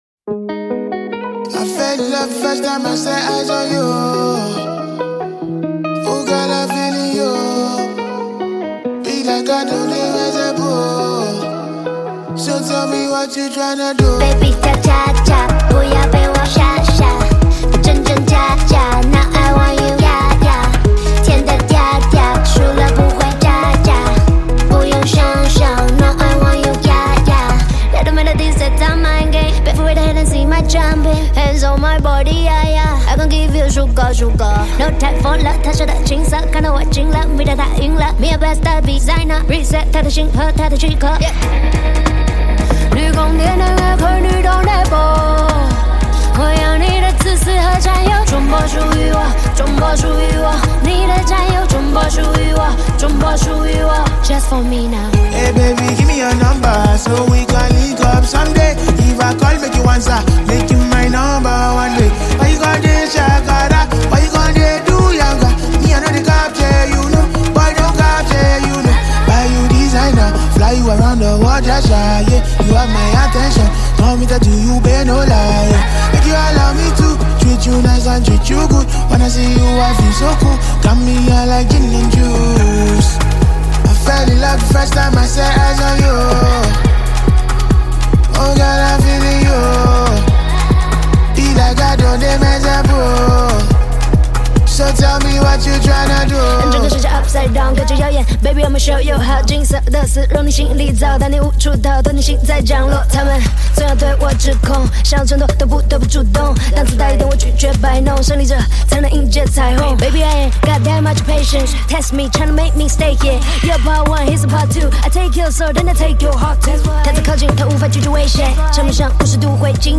an accomplished and well-known Nigerian afrobeat singer